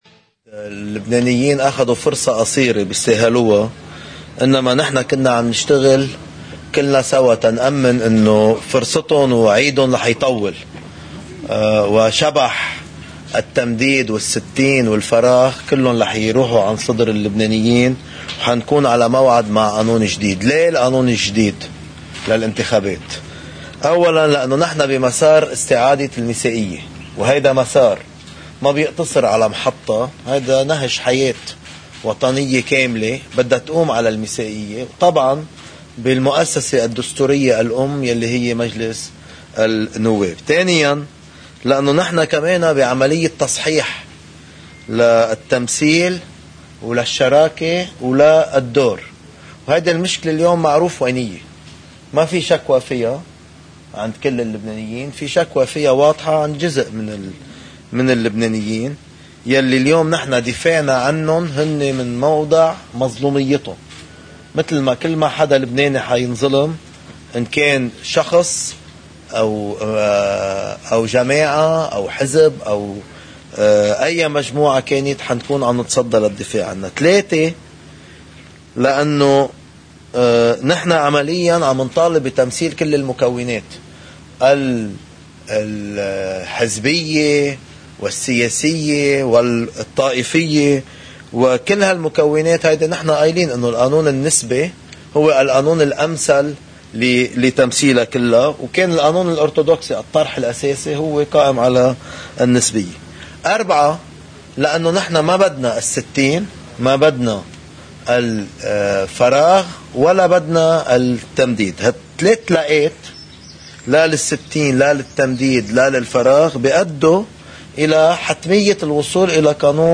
مقتطف من حديث رئيس التيار الوطني الحرّ جبران باسيل بعد اجتماع تكتّل التغيير والإصلاح: